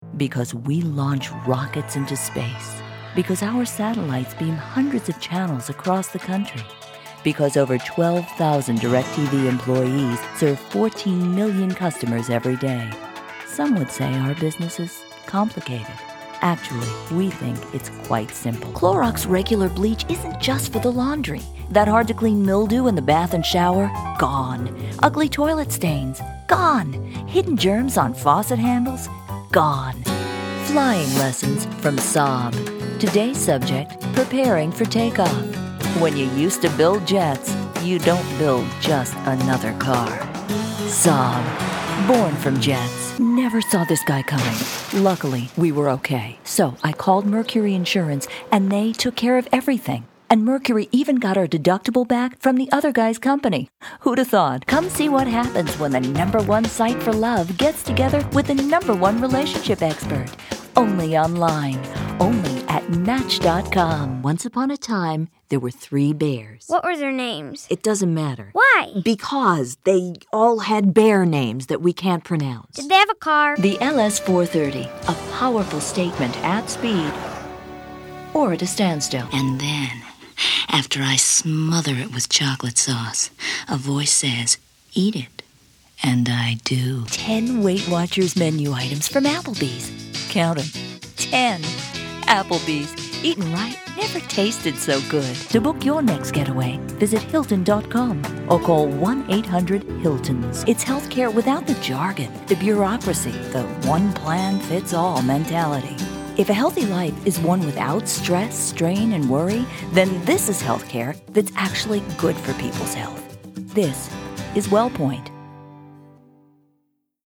Female Voice Over Talent